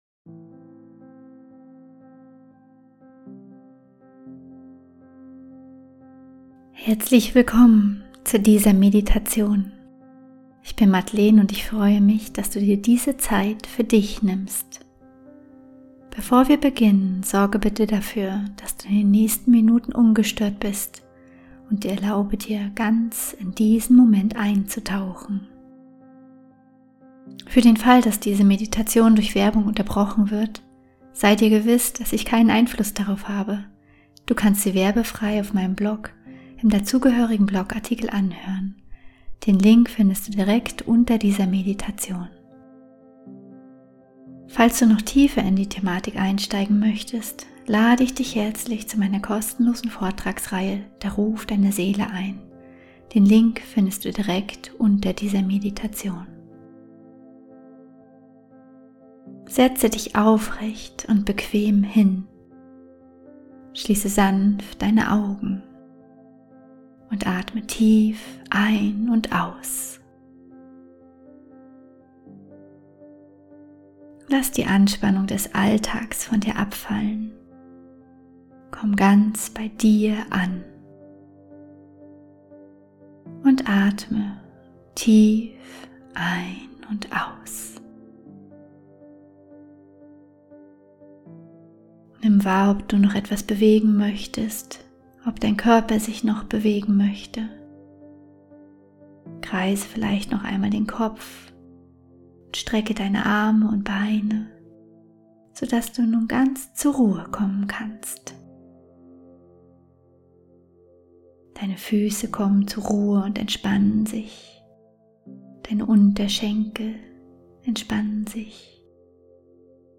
17-Minuten geführte Meditation: Löse deinen inneren Widerstand auf ~ Heimwärts - Meditationen vom Funktionieren zum Leben Podcast
Meditation_Widerstand_loslassen.mp3